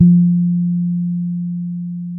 -MM DUB  F 4.wav